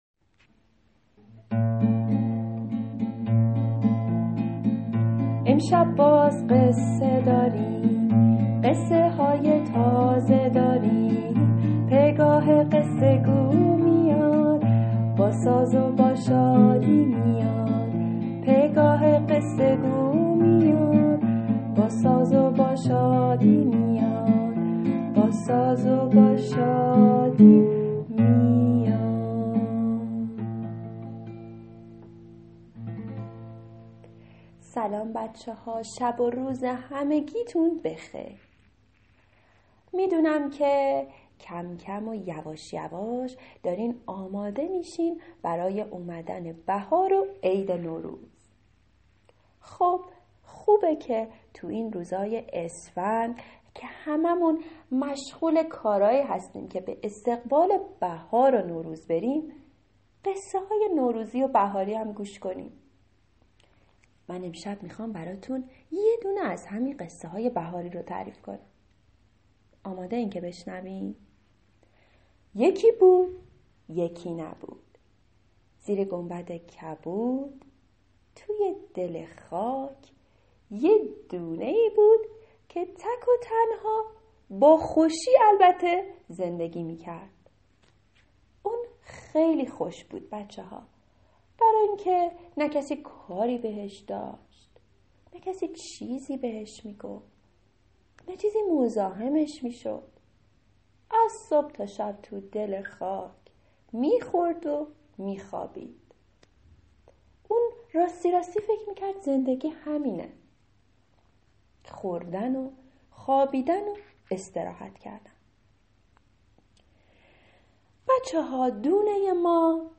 قصه صوتی کودکان دیدگاه شما 705 بازدید